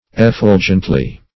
effulgently - definition of effulgently - synonyms, pronunciation, spelling from Free Dictionary Search Result for " effulgently" : The Collaborative International Dictionary of English v.0.48: Effulgently \Ef*ful"gent*ly\, adv. In an effulgent manner.
effulgently.mp3